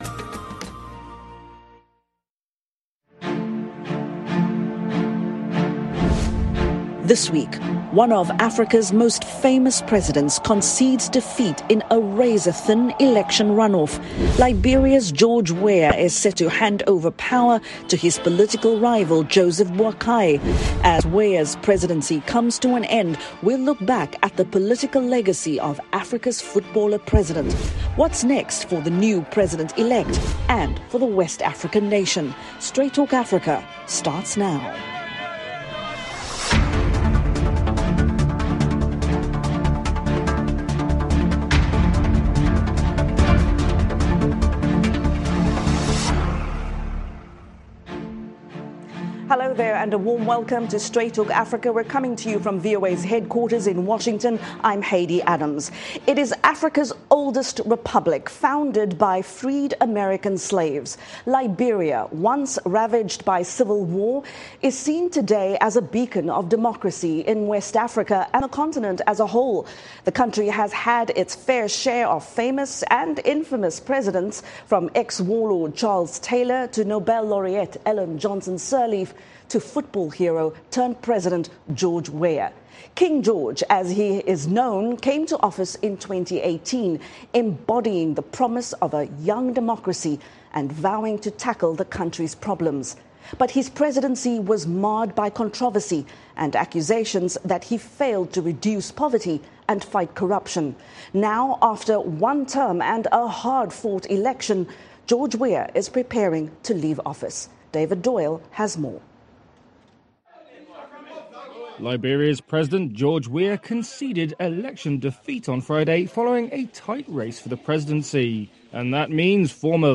an in-depth discussion on the challenges facing the new president-elect